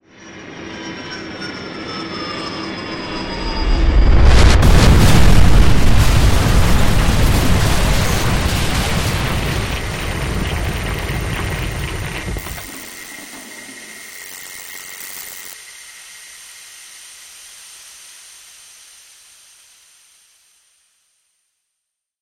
Звук падения космического корабля на землю